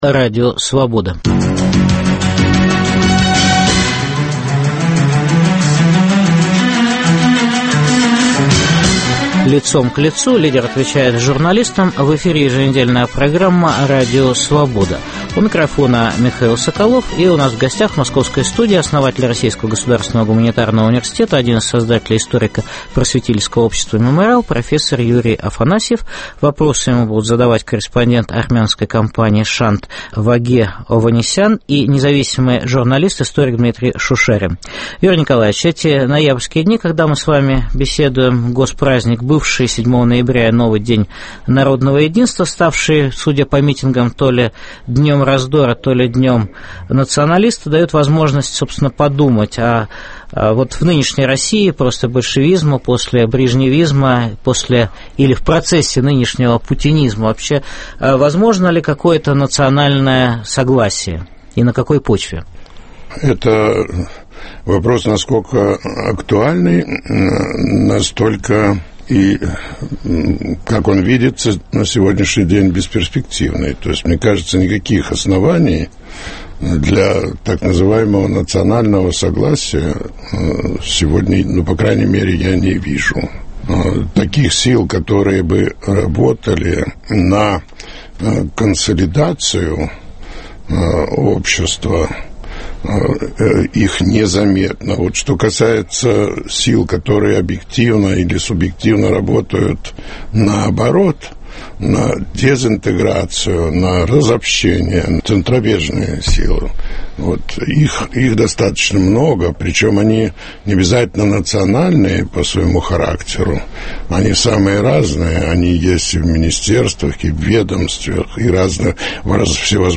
В программе - основатель Российского государственного гуманитарного университета и один из основателей историко-просветительского общества "Мемориал", профессор Юрий Афанасьев.